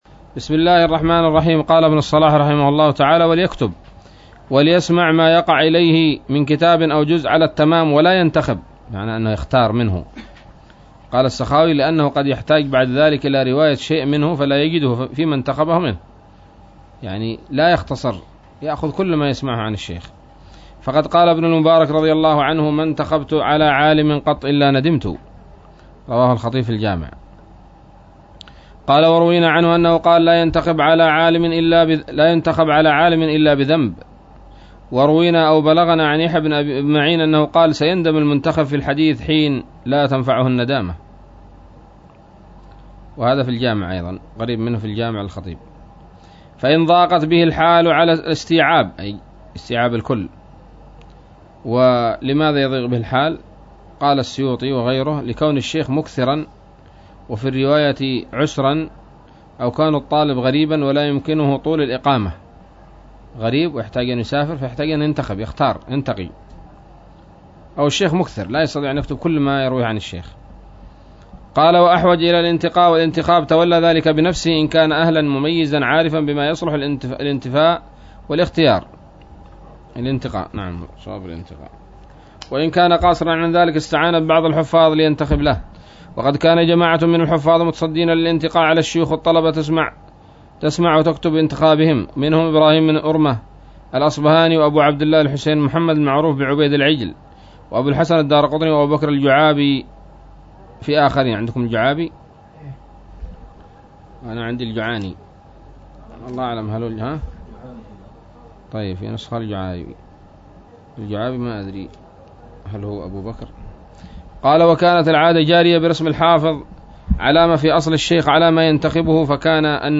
الدرس السابع والثمانون من مقدمة ابن الصلاح رحمه الله تعالى